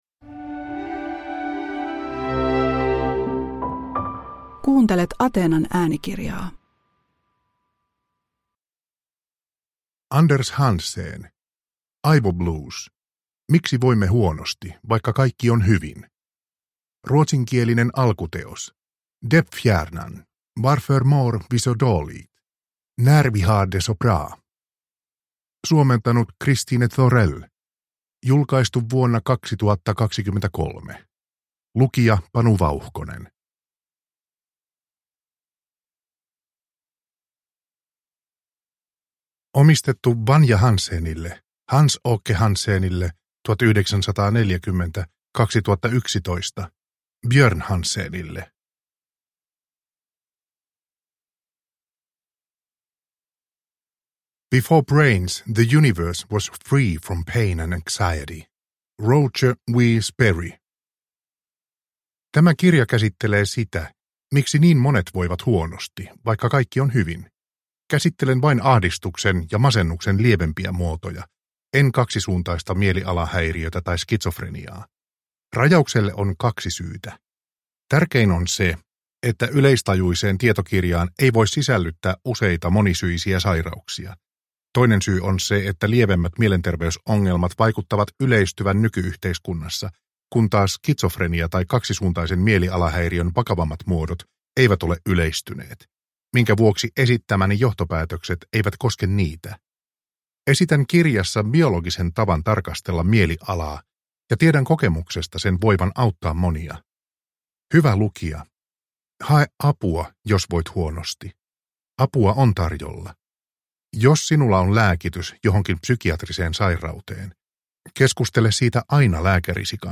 Aivoblues – Ljudbok – Laddas ner